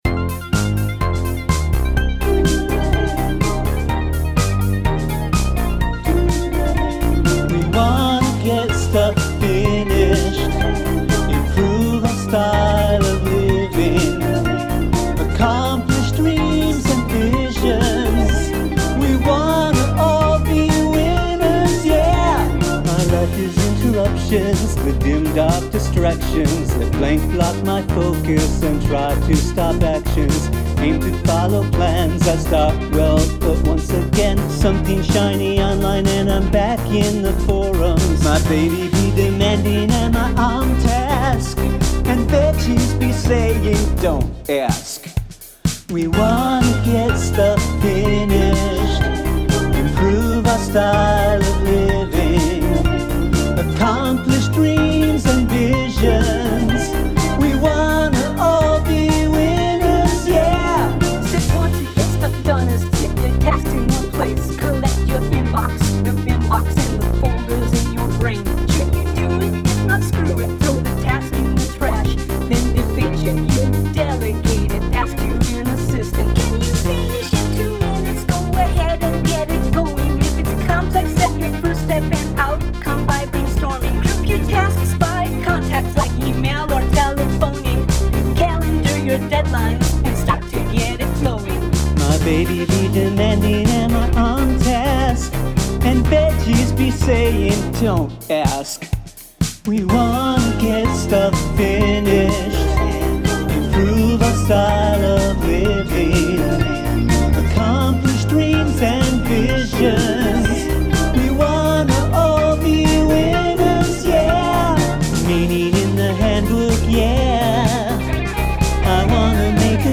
The song has a good beat.